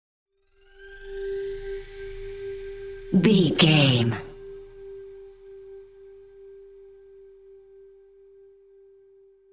Scary Mid Ripple.
In-crescendo
Aeolian/Minor
scary
ominous
suspense
eerie